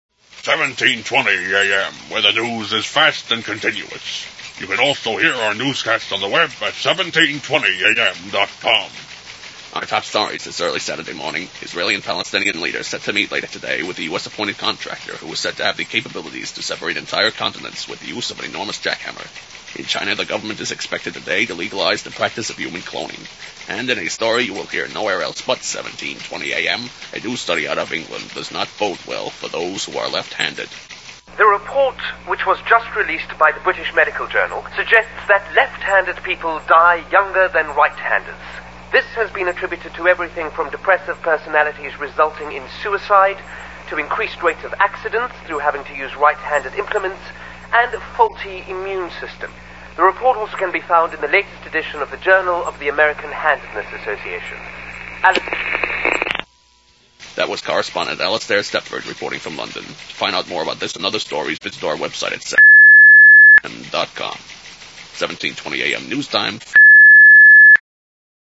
I also ended up using the exact same teletype SFX WINS used.
Here are the "airchecks" of the fictitious "1720" station (about which one person on a board relating to "Noo Yawk's" broadcasting history said, "Love that Group W font"):
Again, you could pretty much tell which station (and its "sound") was satirized in those.
["In early 2000, 1720 AM made a bold step to shore up its presence in the market by acquiring five used Teletype machines . . . for the purpose of providing that annoying 'clack-clack-clack' sound which is heard over our anchors"]